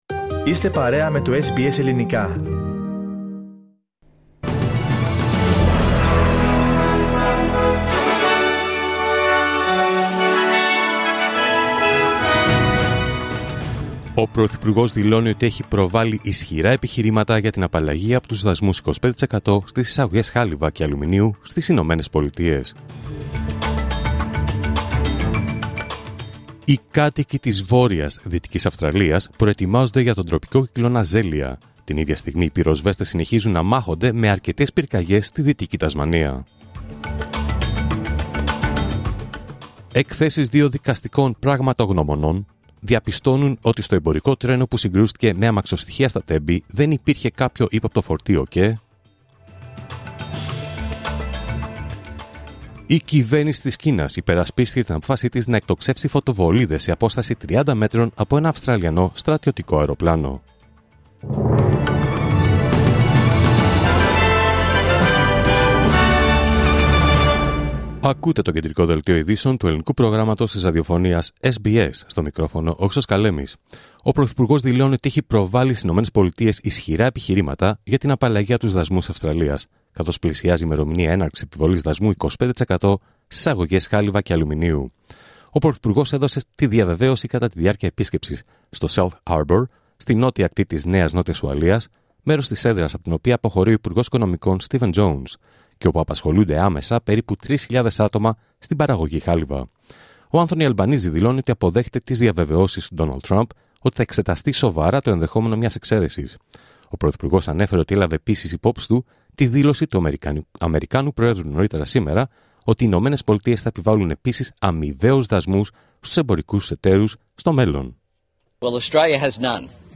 Δελτίο Ειδήσεων Παρασκευή 14 Φεβρουάριου 2025